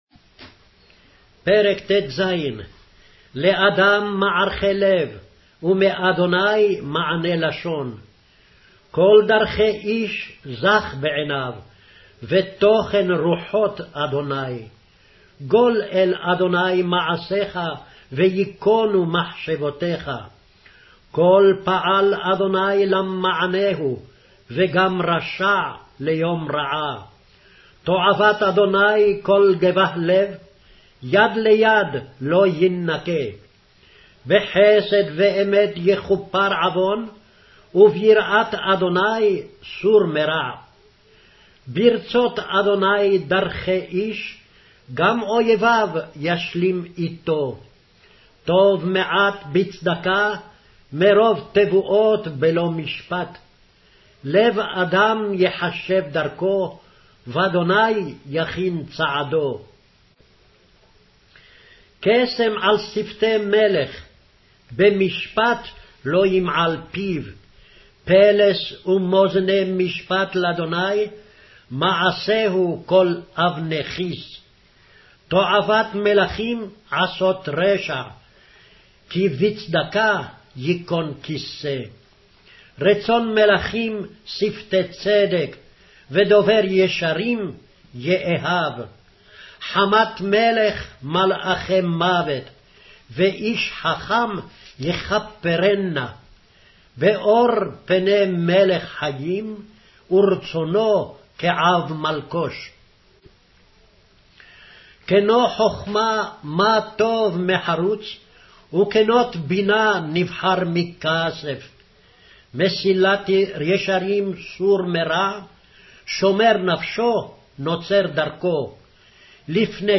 Hebrew Audio Bible - Proverbs 28 in Ervpa bible version